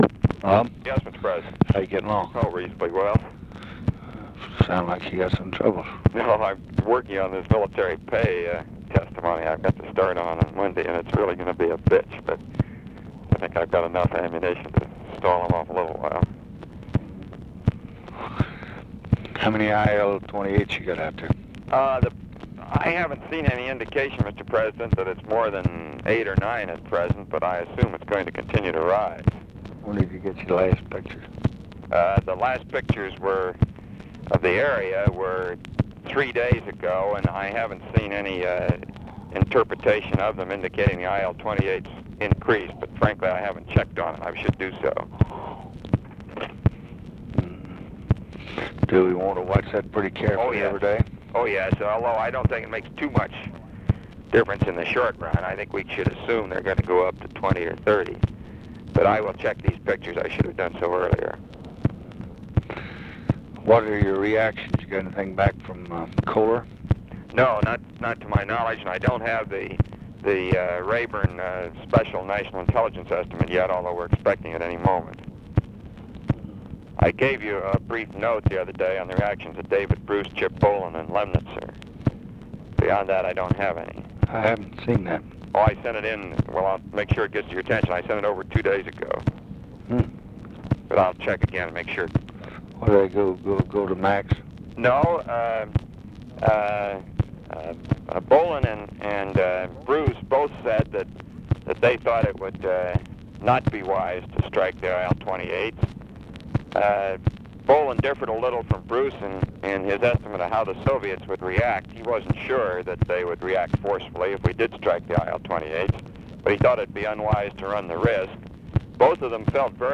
Conversation with ROBERT MCNAMARA, June 5, 1965
Secret White House Tapes